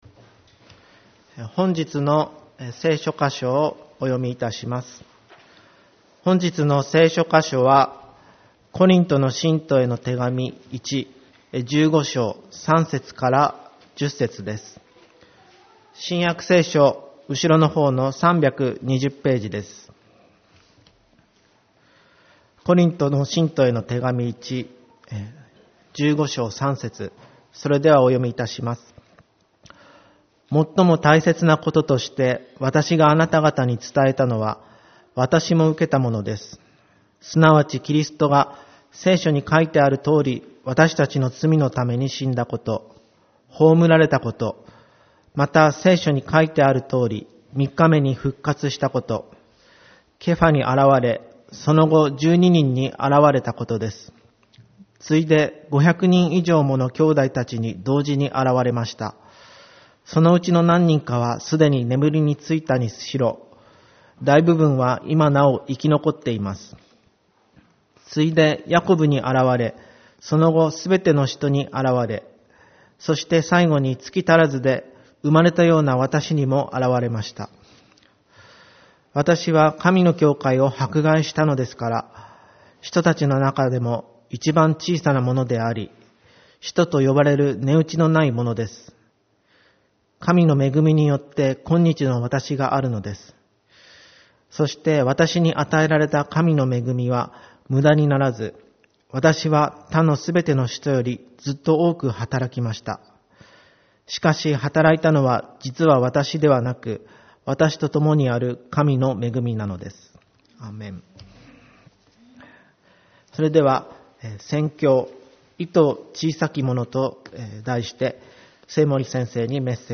主日礼拝 「いと小さき者」 コリントの信徒への手紙①15:3-10